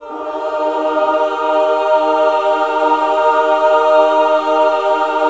Index of /90_sSampleCDs/USB Soundscan vol.28 - Choir Acoustic & Synth [AKAI] 1CD/Partition B/06-MENWO CHD